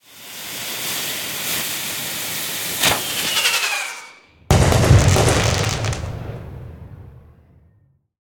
firework.ogg